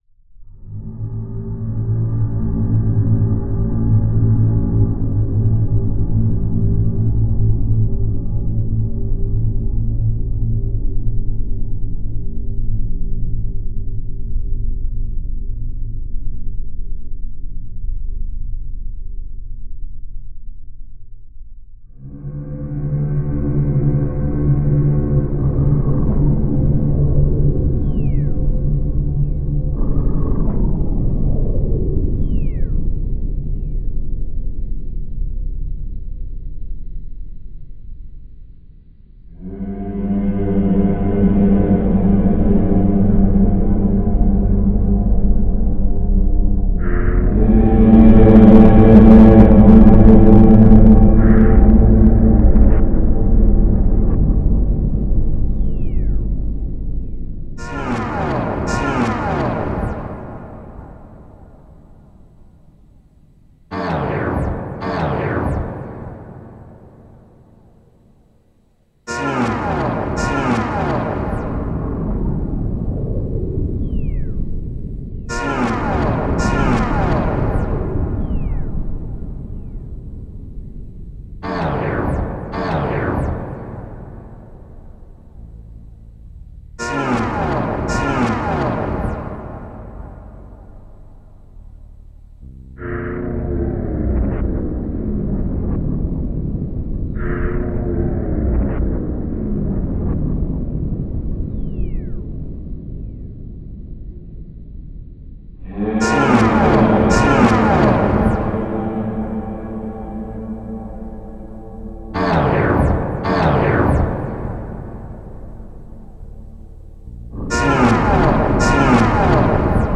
Some of these files contain copyrighted samples of music that have been artistically transformed.
It incorporates digitally modified samples from the following sources: